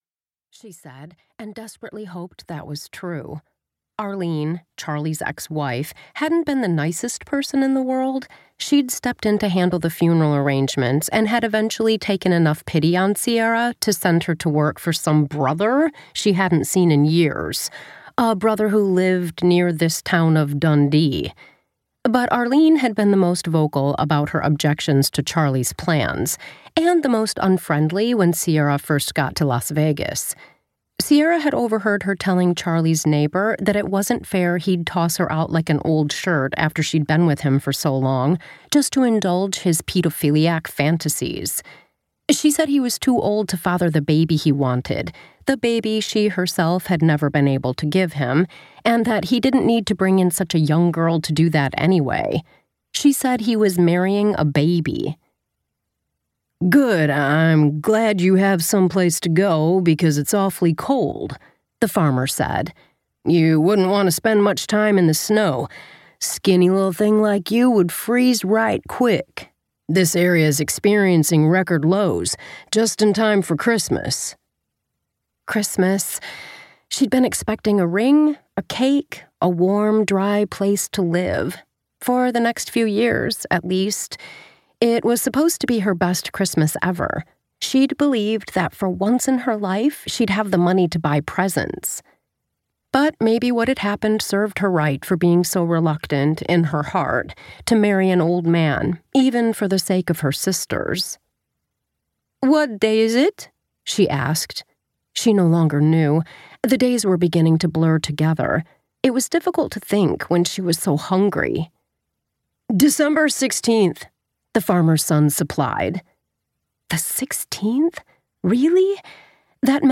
A-Dundee-Christmas-audio_sample.mp3